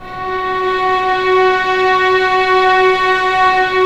Index of /90_sSampleCDs/Roland LCDP13 String Sections/STR_Violins II/STR_Vls6 p wh%